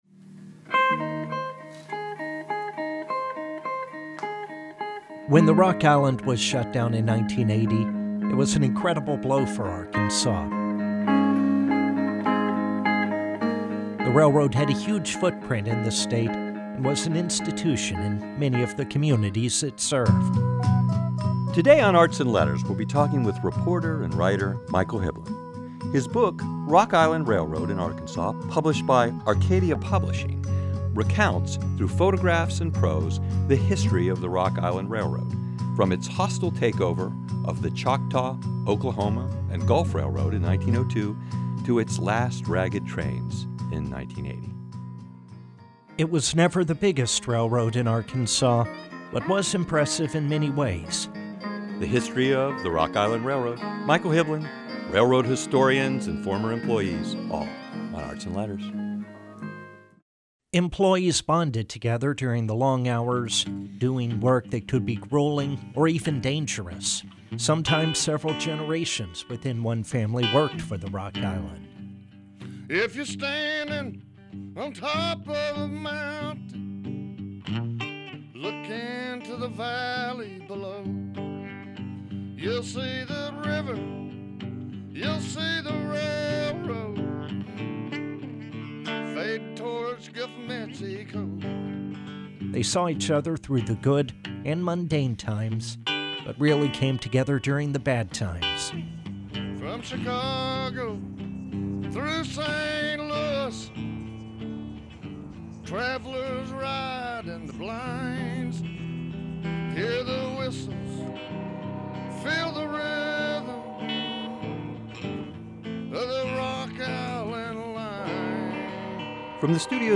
The first episode looks at the regular gatherings of former employees which continue 38 years after the Rock Island was shut down in 1980. In 2016 and 2017 I attended the annual picnics held in Sherwood, Arkansas, setting up a table to record as many stories as possible.